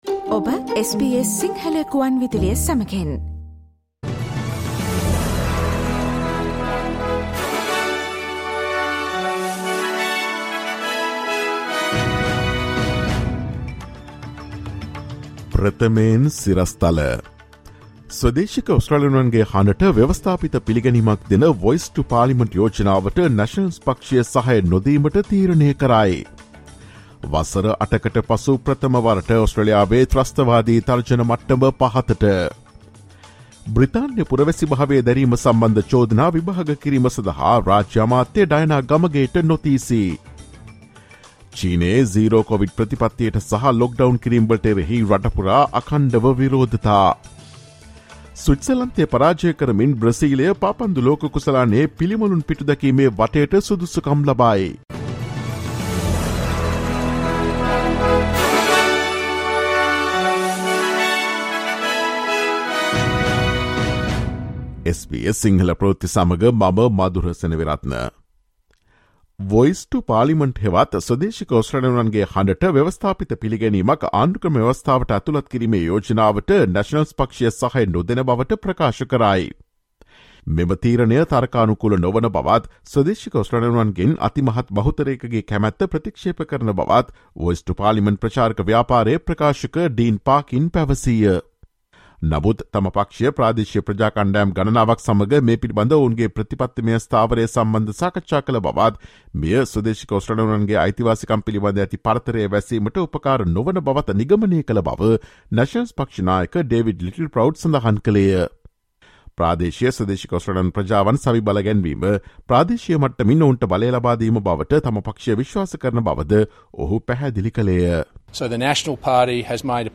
Listen to the latest news from Australia, across the globe, and the latest news from the sports world on SBS Sinhala radio news – Tuesday, 29 November 2022.